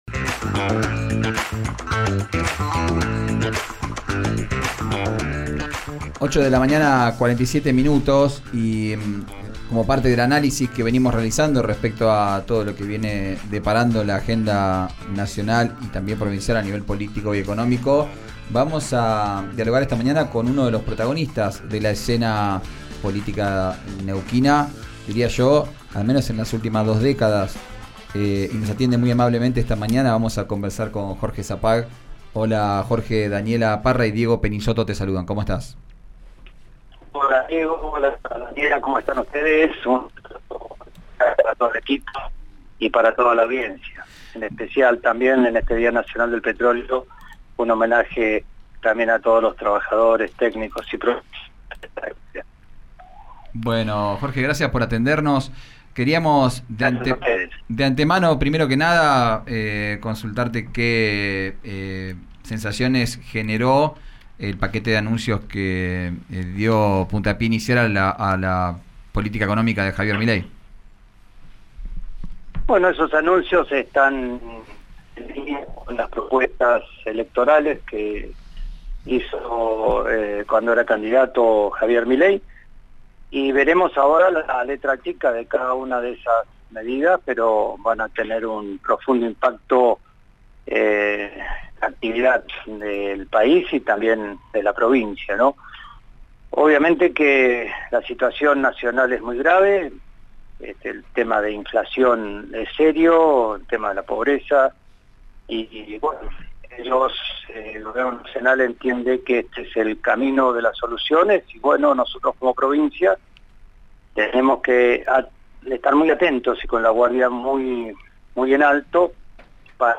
El exgobernador y líder del MPN dialogó con 'Vos al Aire' por RÍO NEGRO RADIO. Dijo que se siente triste porque 'mucha gente del partido quedó afuera del cuadro de toma de decisiones' y destacó los orígenes del flamante mandatario.